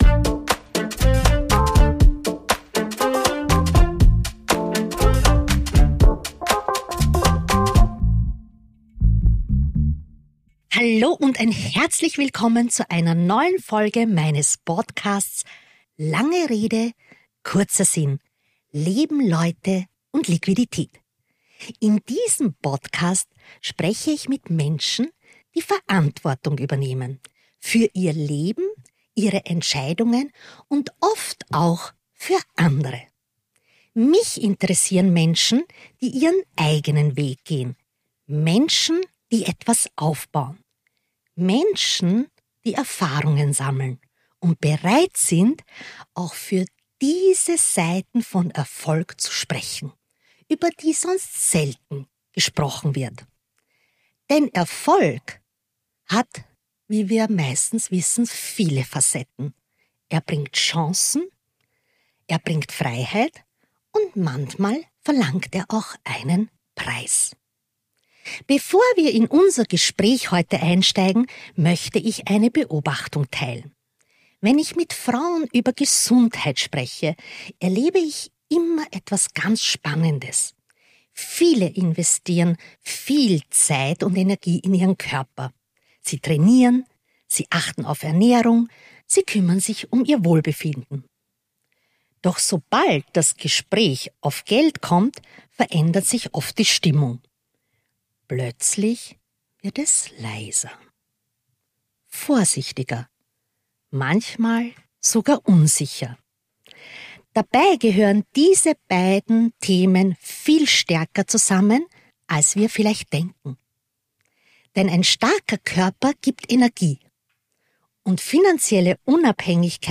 Wir sprechen darüber, warum Gesundheit und Geld enger zusammenhängen als viele denken, welche Herausforderungen Frauen in Beziehungen und im Berufsleben erleben und warum finanzielle Bildung ein zentraler Schlüssel für echte Selbstbestimmung ist. Ein Gespräch über Verantwortung, Unternehmertum, Familie und die Frage, wie Frauen ein Leben gestalten können, das wirklich unabhängig ist.